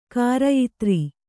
♪ kārayitři